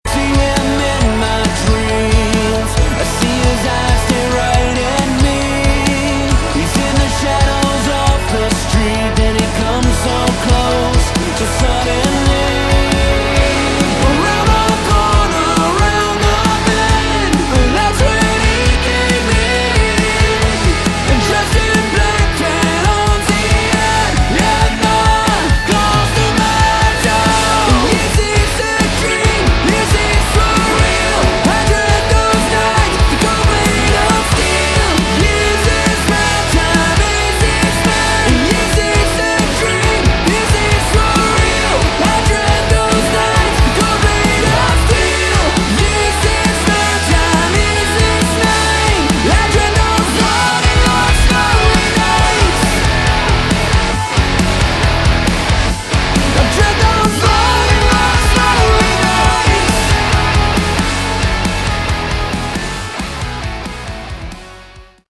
Category: AOR
Vocals, Guitars, Synths
Drums, Percussion
An handsome piece of modern AOR, brilliant !